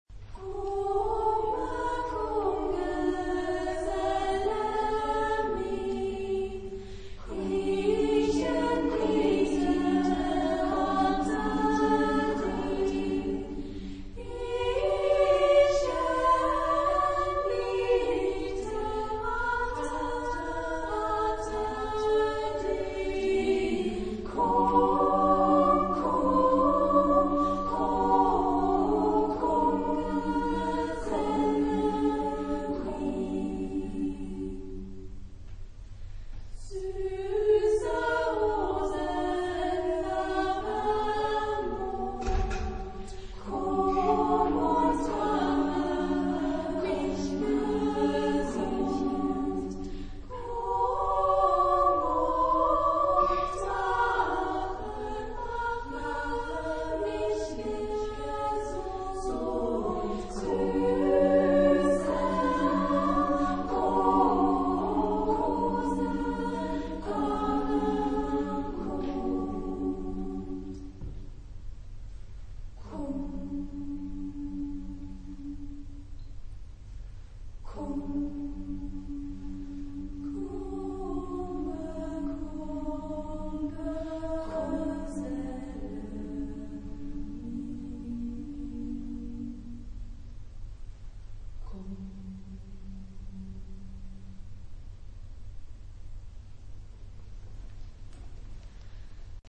Genre-Stil-Form: Liedsatz ; weltlich
Chorgattung: SSAA  (4 Frauenchor Stimmen )
Tonart(en): G-Dur
von Acerva Oberspreewald-Lausitz gesungen
Aufnahme Bestellnummer: 7. Deutscher Chorwettbewerb 2006 Kiel